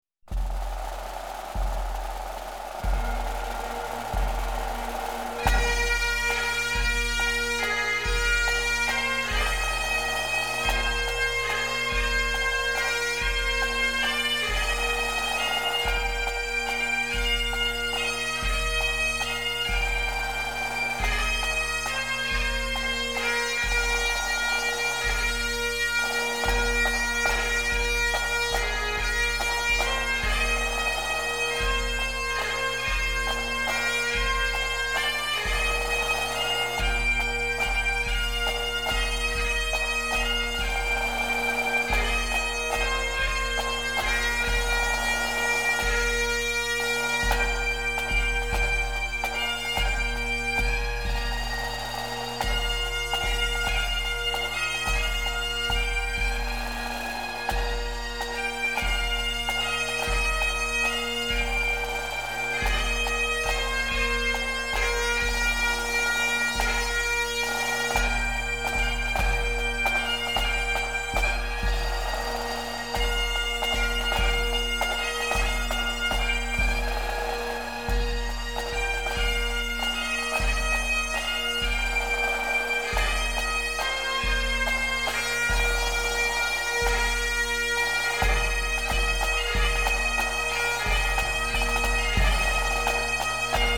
Scots Wha Hae (bagpipes) by the 48th Highlanders of Canada (7MB, .mp3, 3m14s)